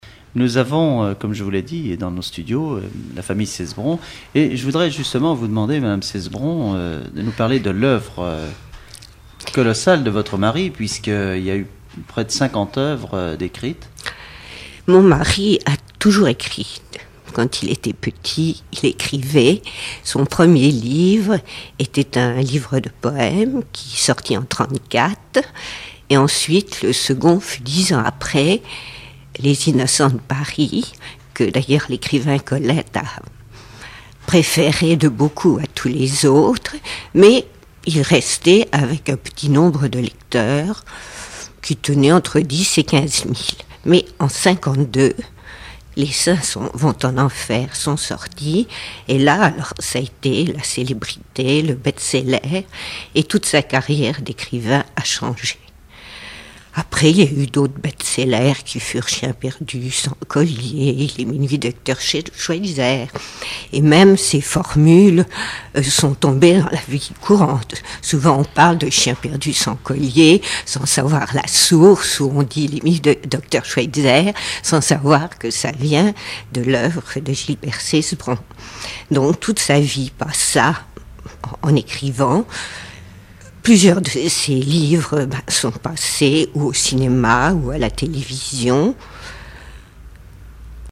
numérisation d'émissions par EthnoDoc
Catégorie Témoignage